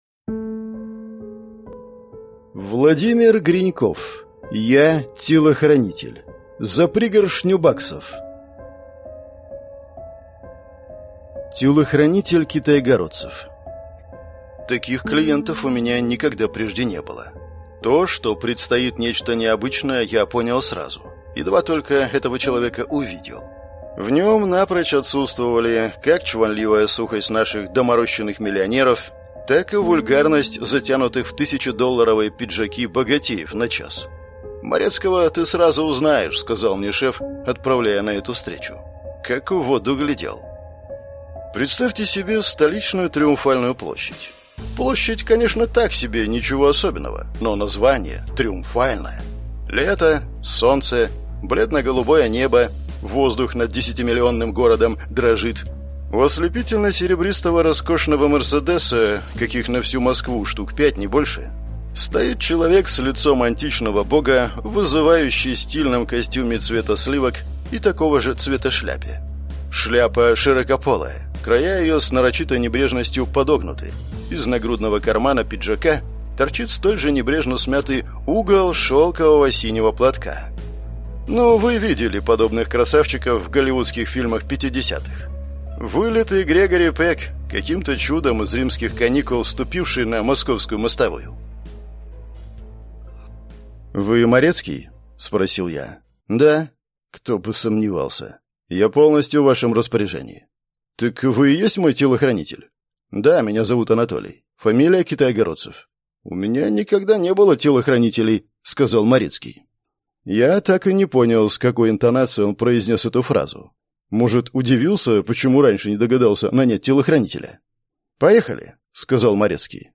Аудиокнига Я – телохранитель. За пригоршню баксов | Библиотека аудиокниг